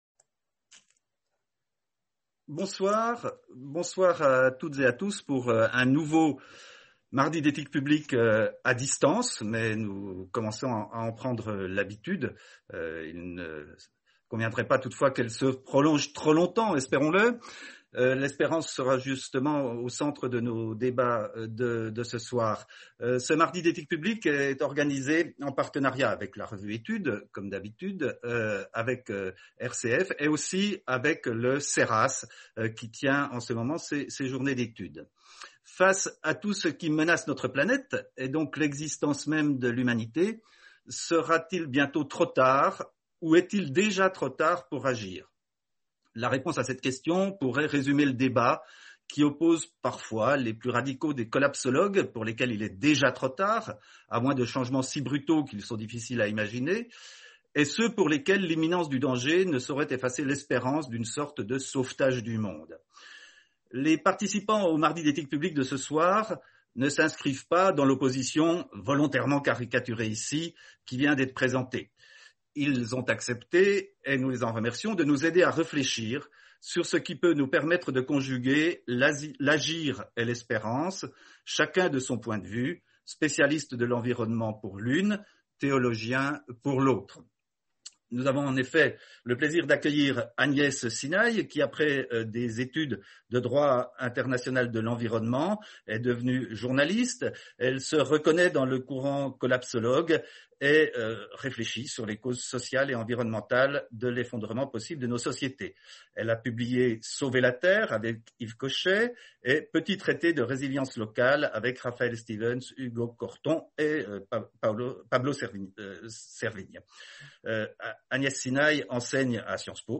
Soirée animée